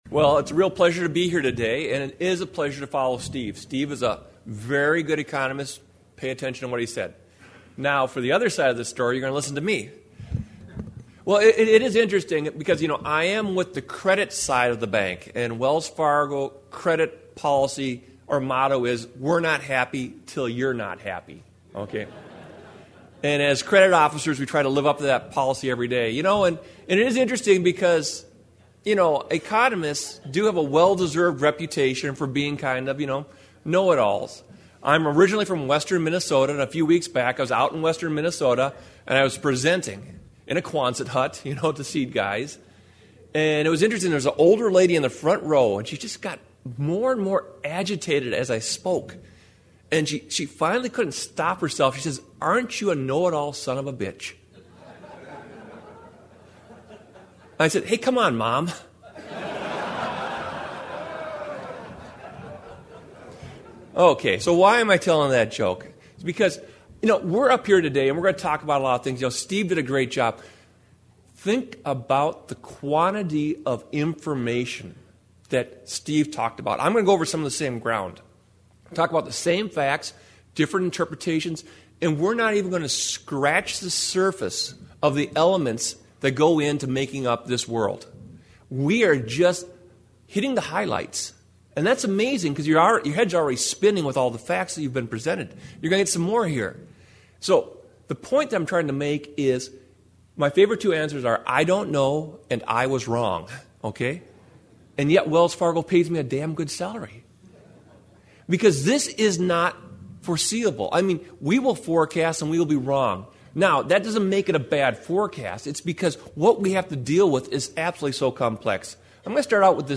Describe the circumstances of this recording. It was a morning of economics at the AgroNomics Conference.